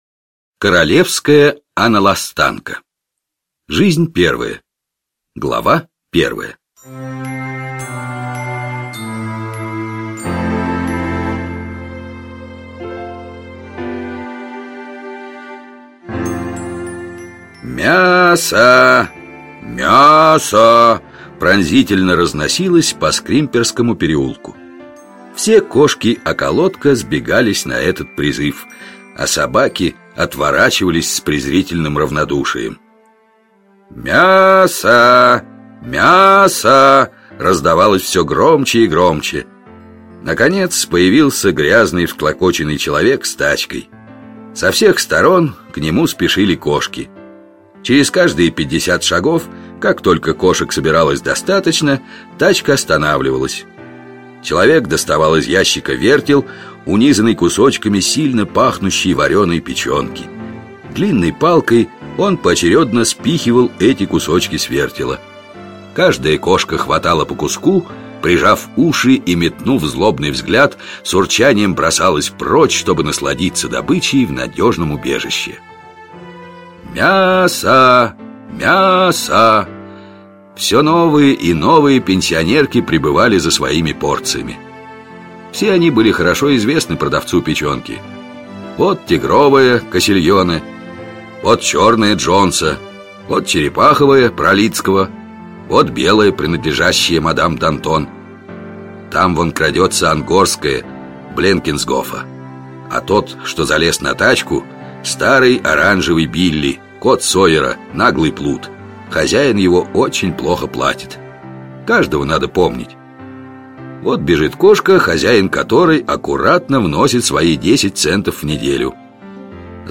Королевская Аналостанка 🐈 слушать аудиокнигу онлайн
Здесь вы можете слушать онлайн аудиокнигу "Королевская Аналостанка" Эрнеста Сетон-Томпсона о кошке, которой больше нравилось жить в трущобах, нежели в роскоши.